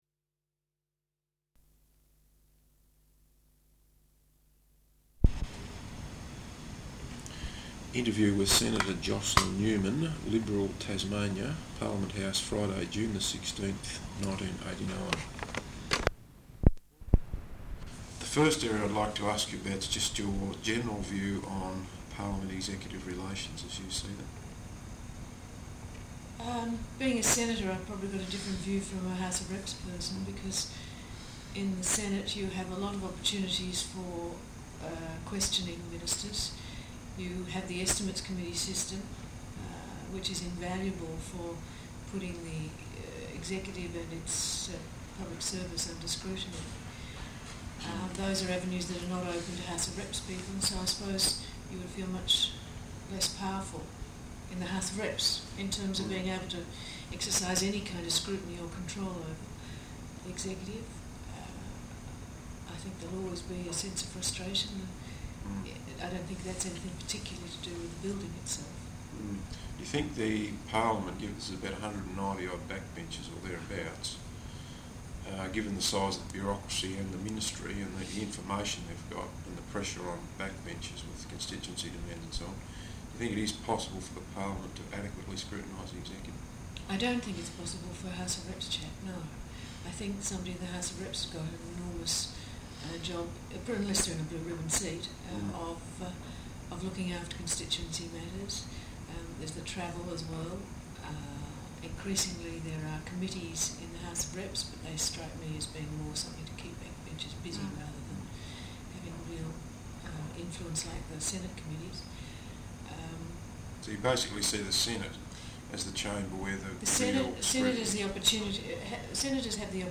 Interview with Senator Jocelyn Newman, Liberal, Tasmania, Parliament House, Friday 16 June, 1989